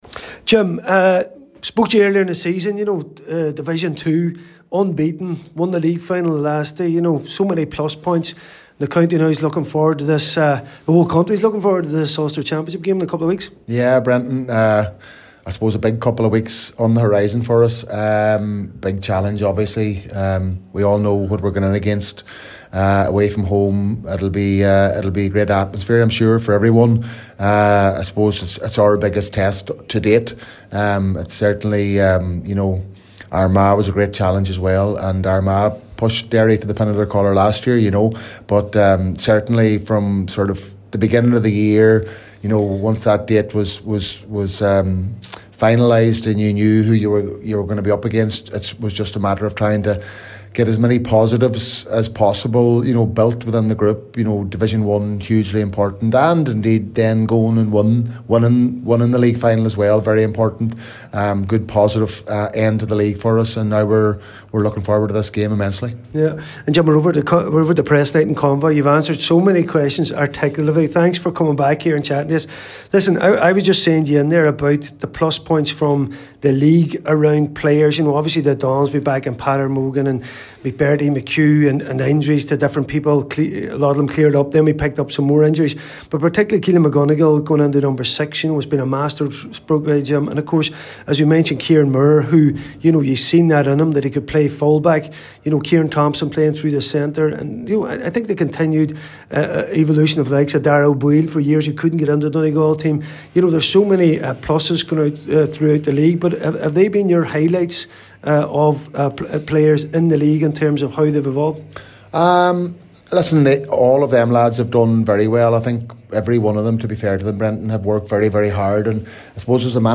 at a press event in Convoy this evening, McGuinness talked openly about the scale of the challenge ahead.
Here’s the full interview…